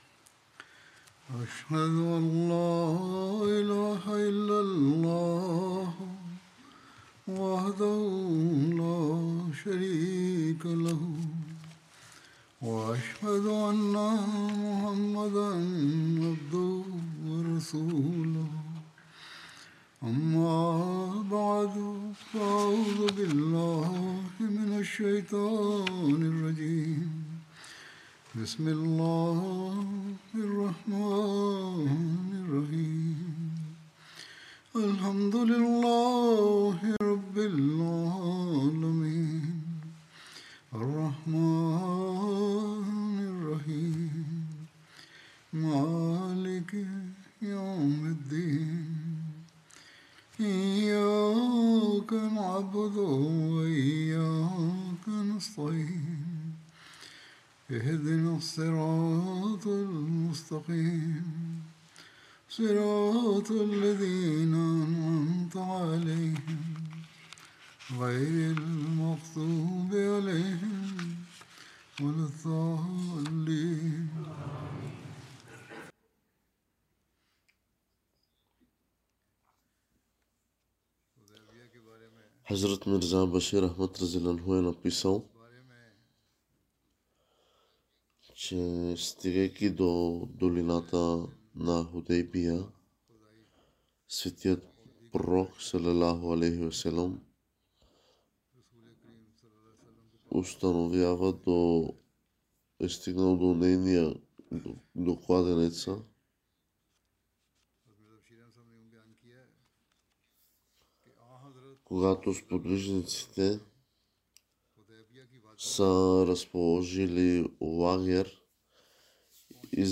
Bulgarian Translation of Friday Sermon delivered by Khalifatul Masih